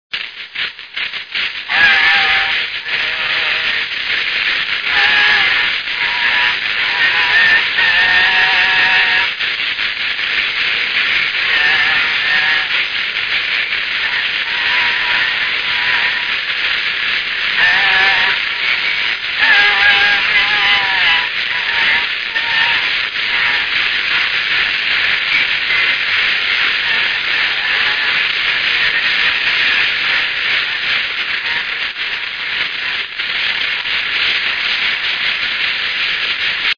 Alföld - Pest-Pilis-Solt-Kiskun vm. - Bogyiszló
Gyűjtő: Lajtha László
Stílus: 1.3. Ereszkedő moll népies műdalok